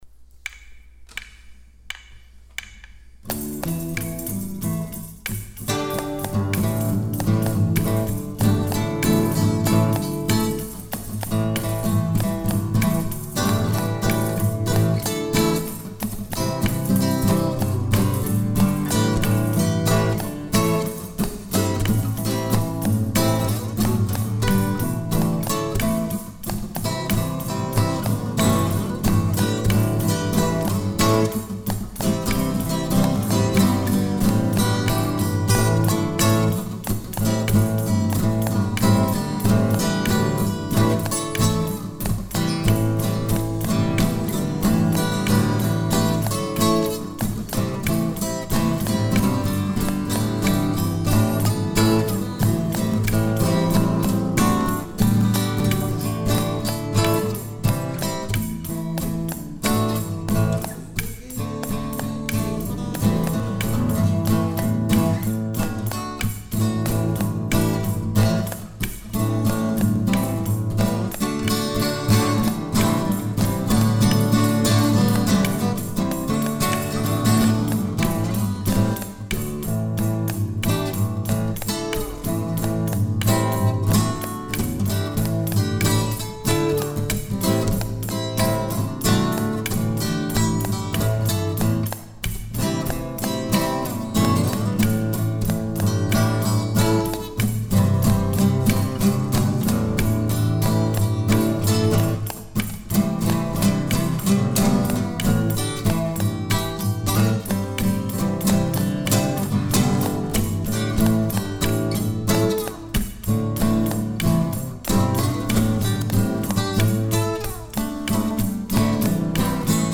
instrumental
So I left the vocals out
A response to your rhythmic and bewitching chord.